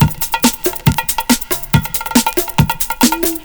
PERCLOOP2-R.wav